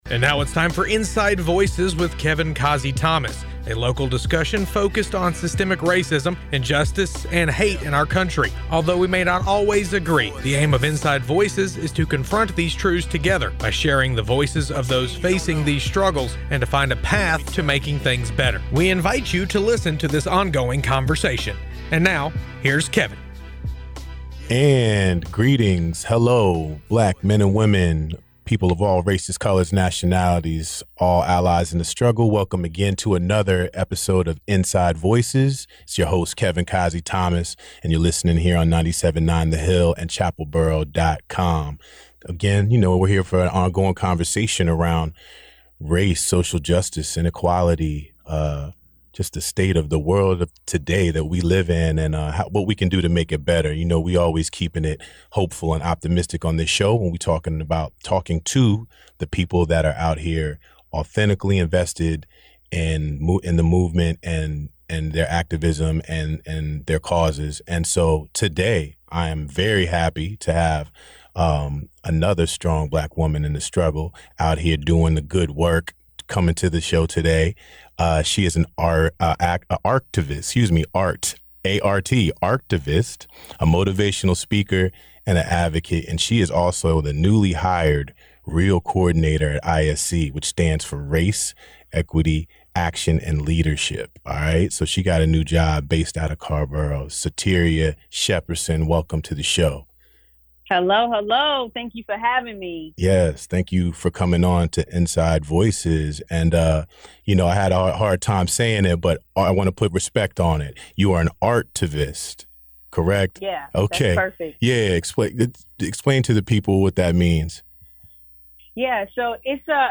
for a conversation